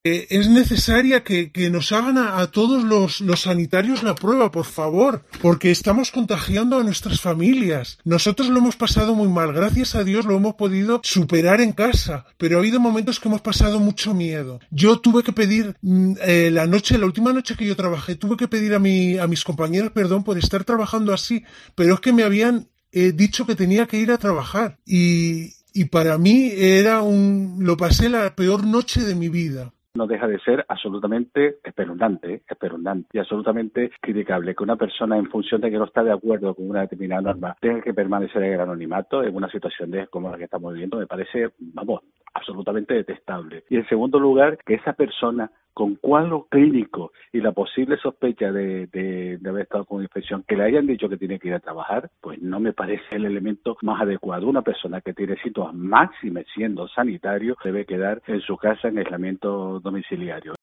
Enfermera anónima y Amos García, , jefe de Epidemiología y Prevención de la Dirección General de Salud Pública
Esta enfermera denunció en COPE Canarias con voz distorsionada su caso.